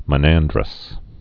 (mə-năndrəs)